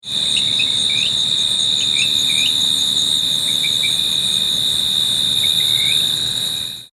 Rio Grande Chirping Frog - Eleutherodactylus cystignathoides campi